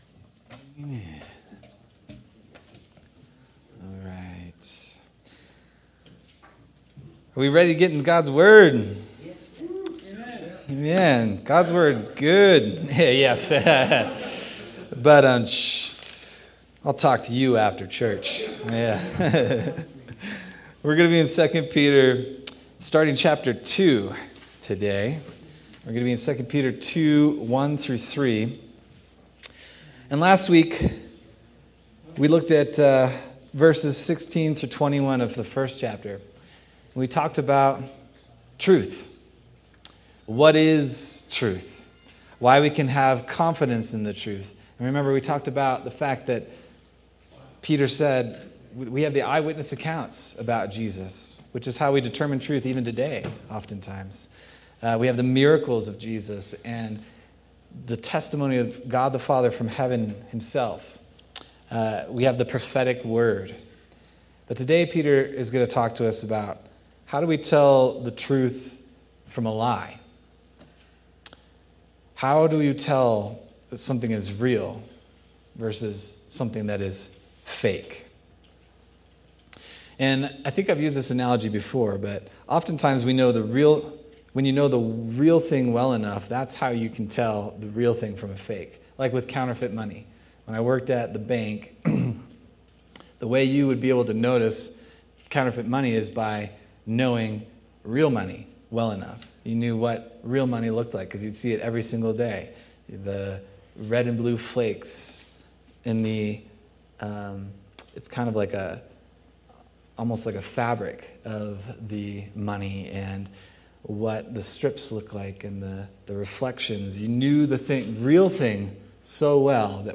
October 19th, 2025 Sermon